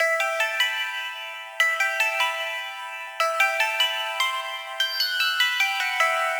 150BPM Lead 15 Emin.wav